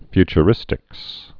(fychə-rĭstĭks)